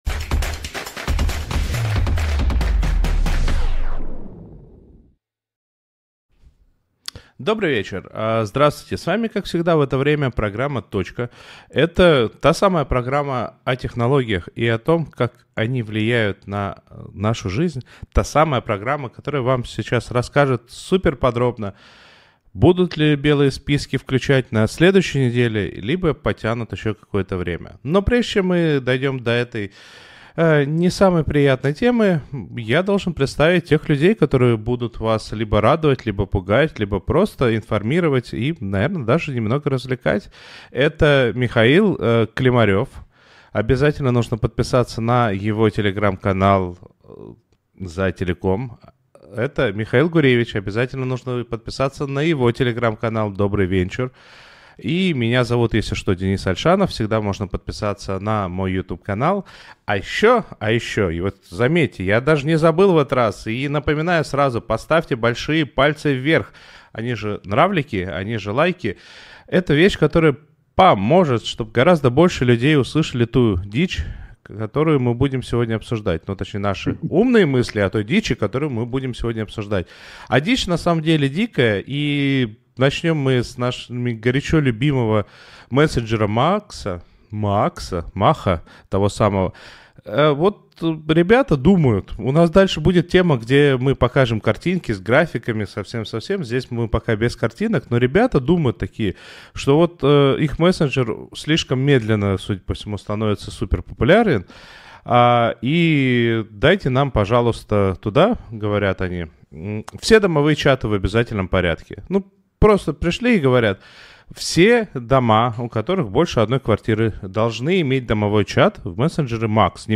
говорит с экспертами про интернет и технологии в нашей жизни